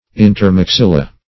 Search Result for " intermaxilla" : The Collaborative International Dictionary of English v.0.48: Intermaxilla \In`ter*max*il"la\, n.; pl.